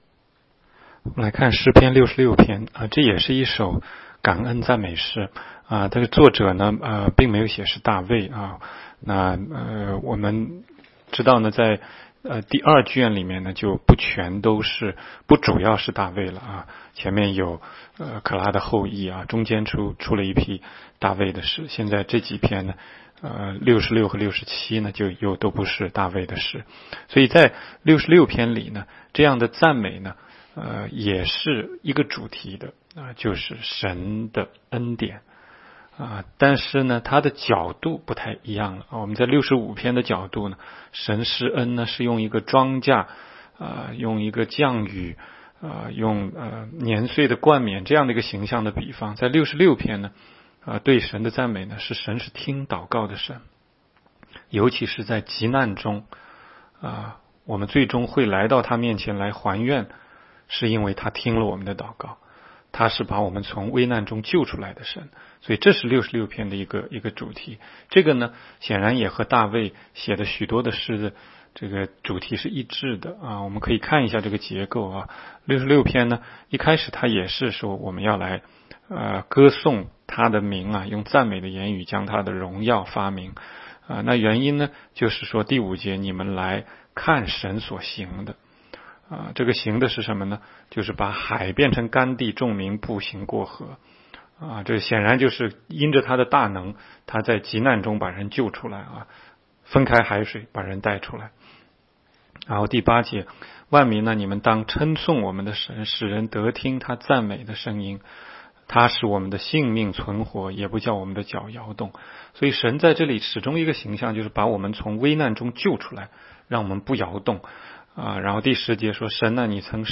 16街讲道录音 - 每日读经-《诗篇》66章